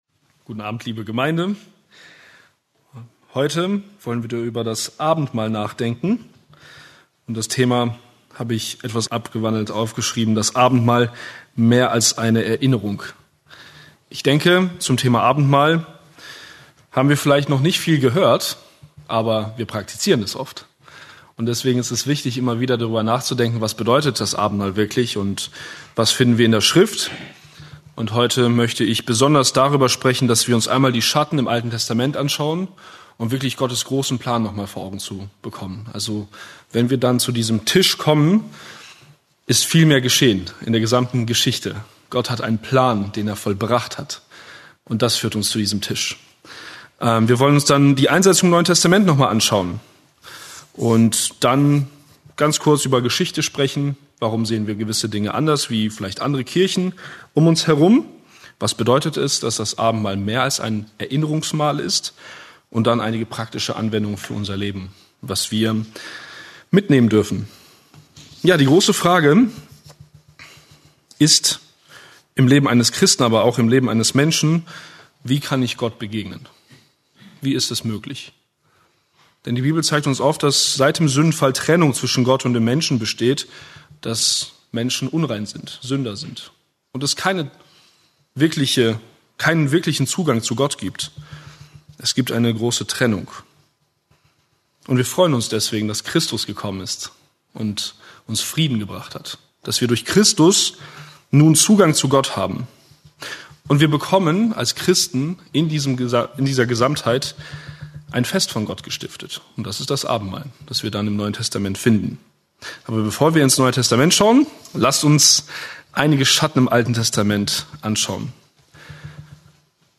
Eine predigt aus der serie "Einzelpredigten."